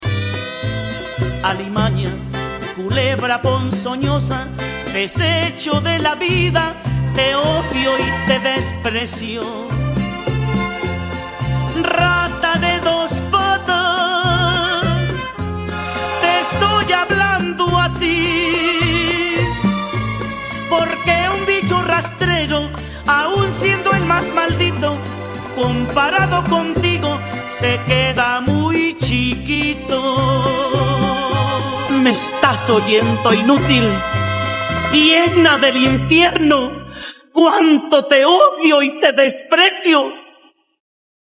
Musica regional Mexicana